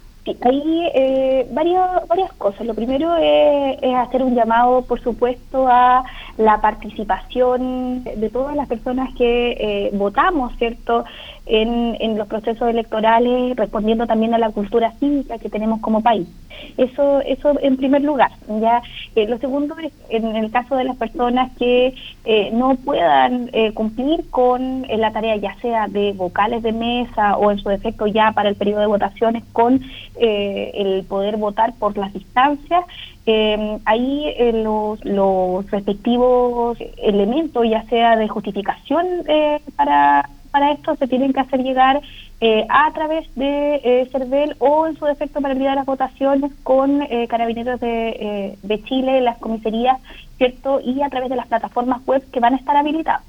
Es muy importante presentar las excusas correspondientes ante las Juntas Electorales, tanto si no pueden desempeñar la labor de vocales de mesa como si no se pude acudir el día de las elecciones, como indicó la Seremi de Gobierno en Los Lagos, Danitza Ortiz.